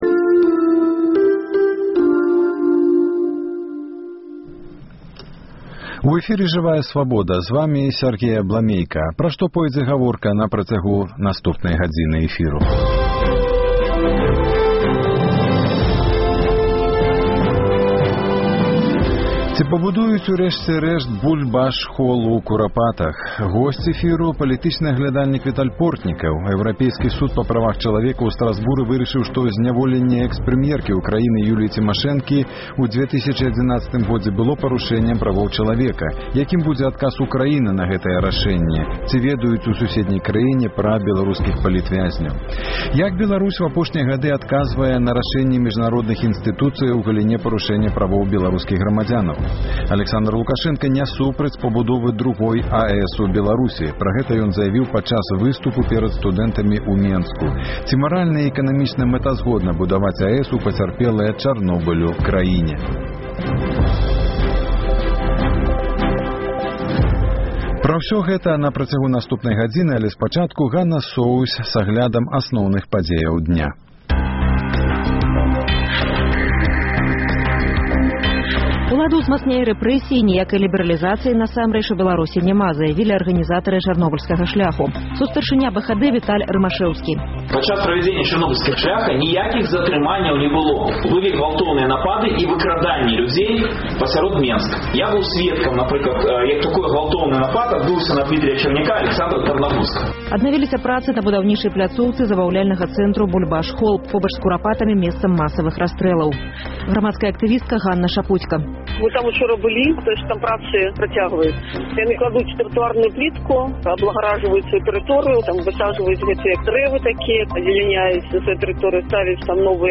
Ці пабудуюць “Бульбаш-хол” у Курапатах? Госьць эфіру — палітычны аглядальнік Віталь Портнікаў.